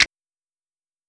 Snap (From Time).wav